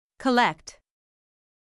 しいて言えば、「ア」「ウ」「エ」「オ」の中間のような音になります。
どの母音ともつかない曖昧な発音をするのがポイントです。